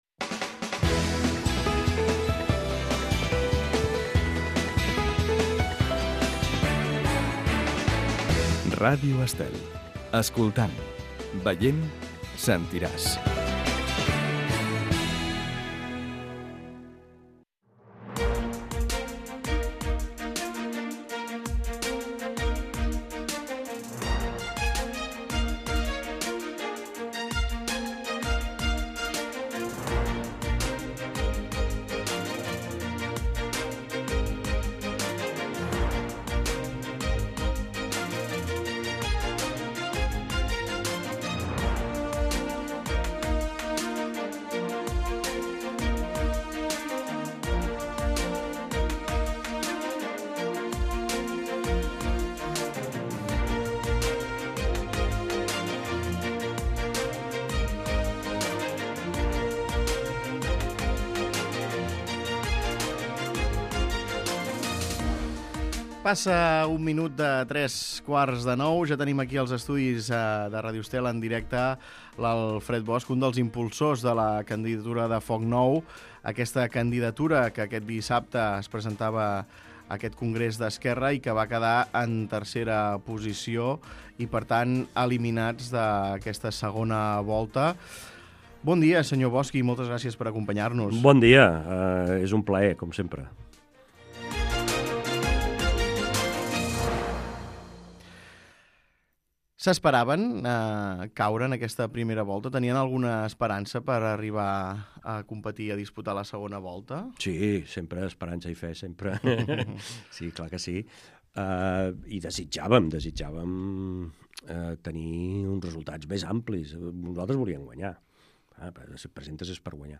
Escolta l'entrevista d'Alfred Bosch, candidat de Foc Nou a la secretaria general d'Esquerra Republicana